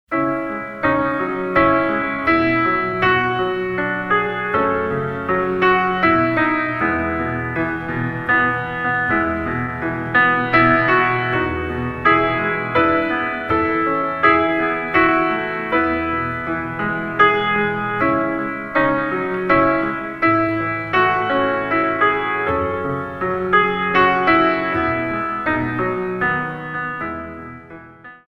In 3